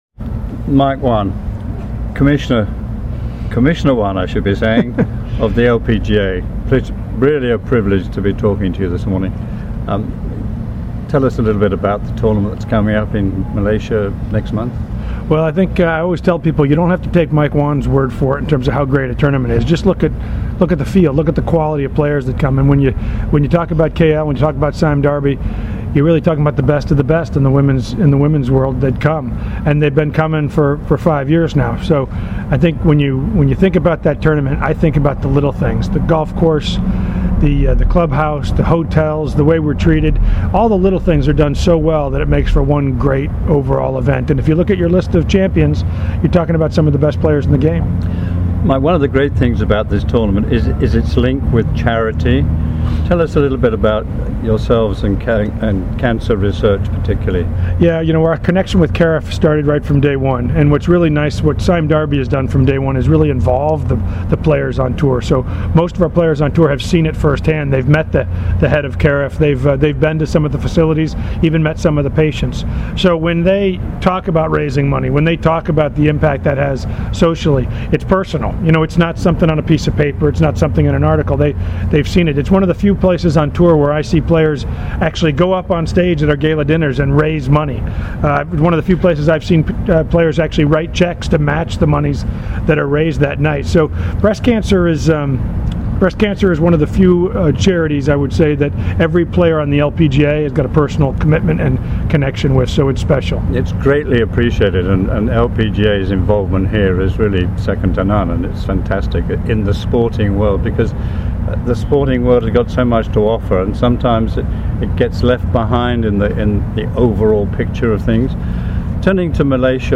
interviews LPGA Commissioner Michael Whan on the upcoming Sime Darby LPGA Malaysia, breast cancer awareness, and golfing in Malaysia.